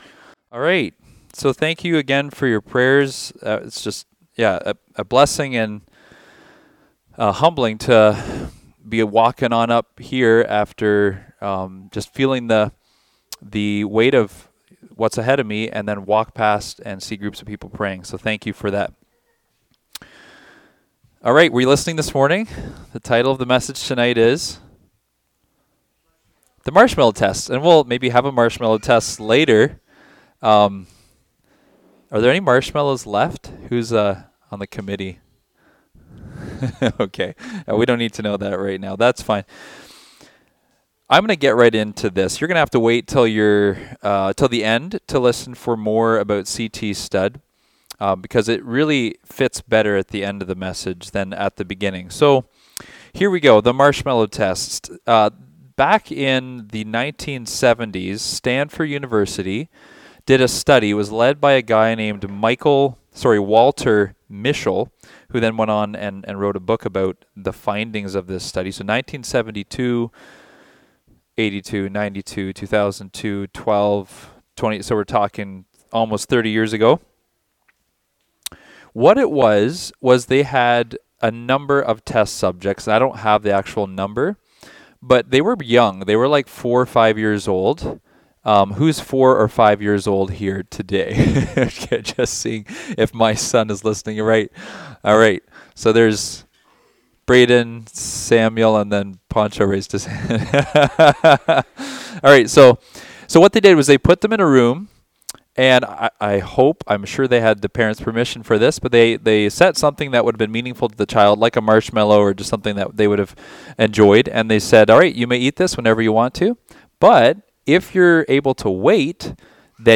Topic: Life Choices Service Type: Youth Meetings